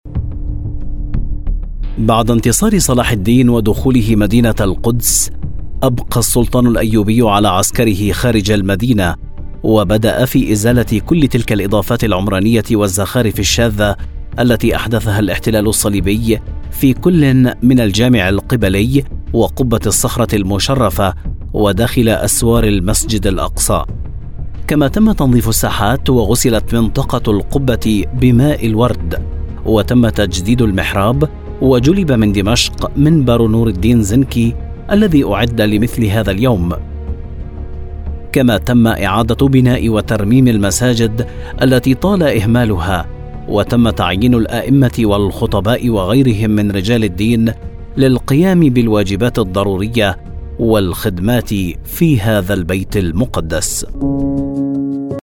Documentales
Audiolibros
Adulto joven
Mediana edad